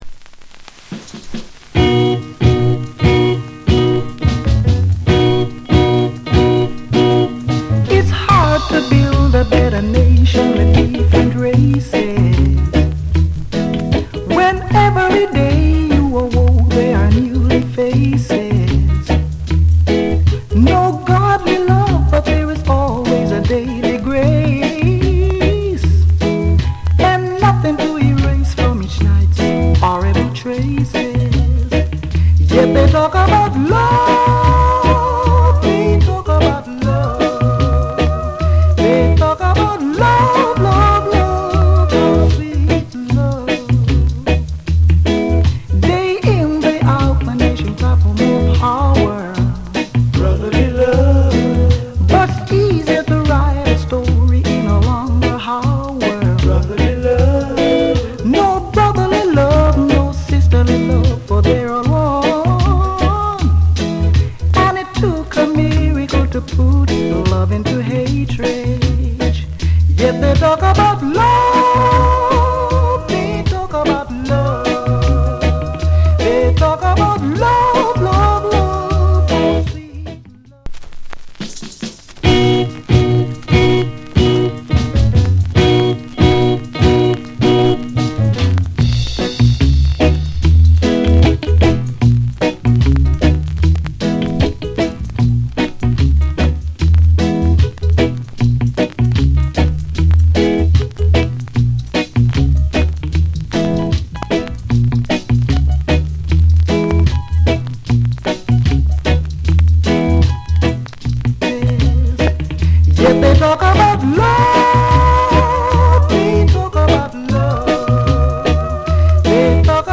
Genre Reggae70sEarly / Male Vocal